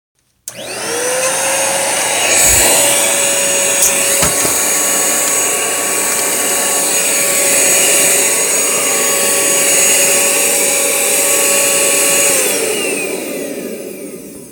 Field Recording
Vacuum Cleaner (and me dropping said vacuum cleaner)
Vacuum-Cleaner.mp3